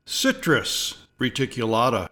Pronounciation:
CI-trus re-ti-cue-LA-ta